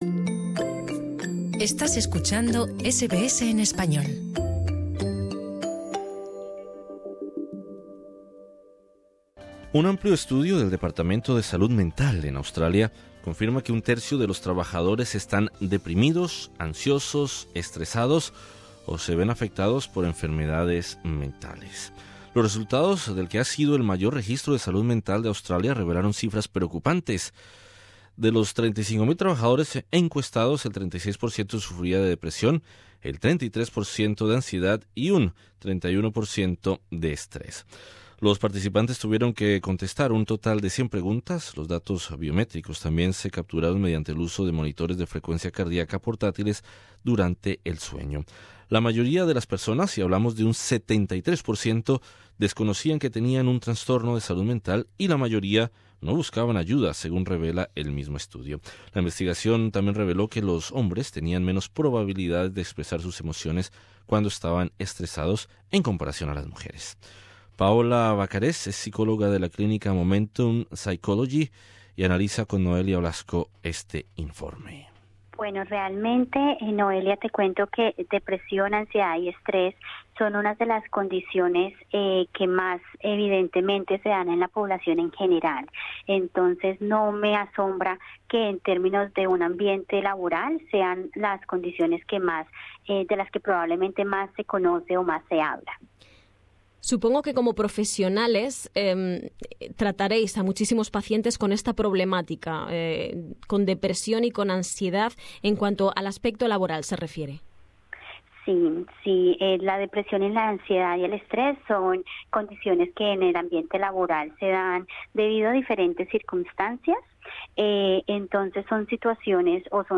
De los 3500 trabajadores encuestados, el 36 por ciento sufrían de depresión, 33 por ciento de ansiedad y 31 por ciento de estrés. Entrevista